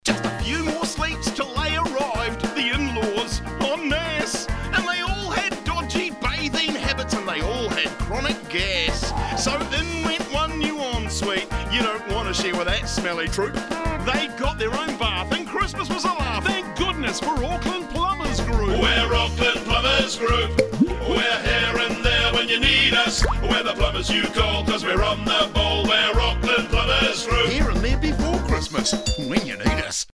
Our festive jingle is here to remind you to tackle those plumbing jobs before the Christmas rush – and keep holiday stress at bay.
Radio-advert-Inlaws-0924.mp3